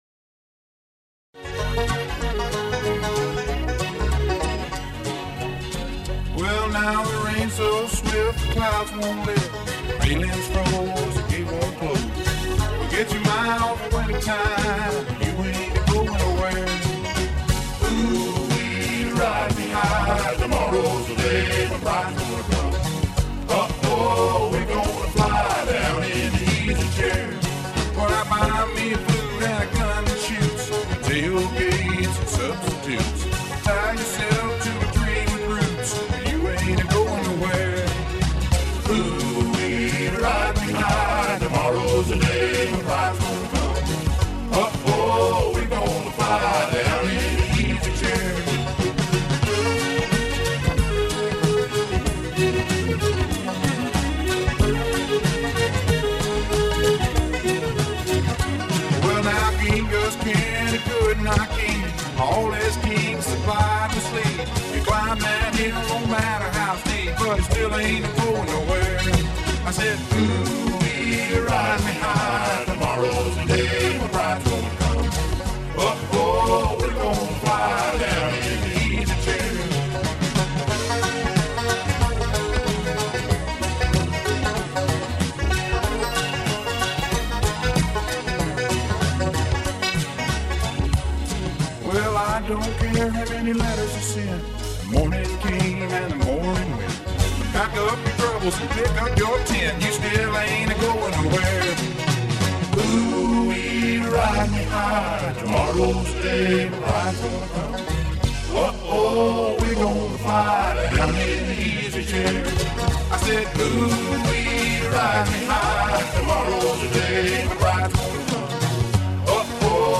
Country - Rock